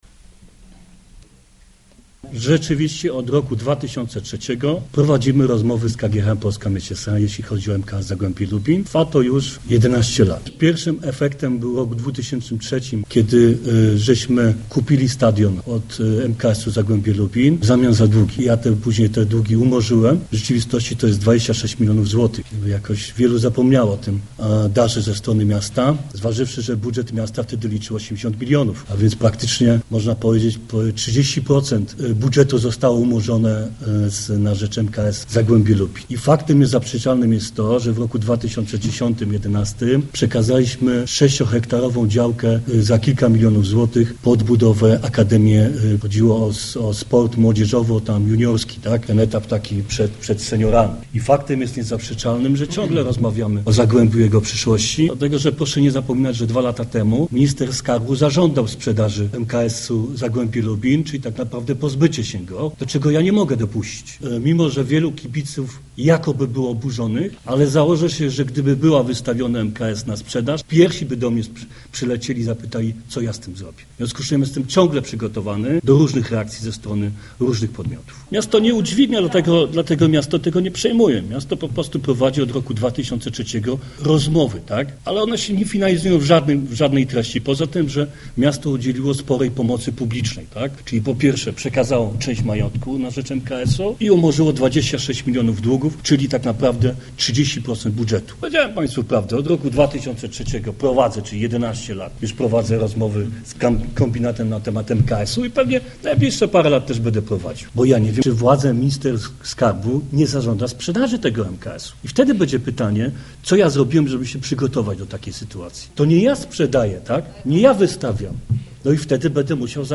Pełna wypowiedź prezydenta Lubina na temat Zagłębia: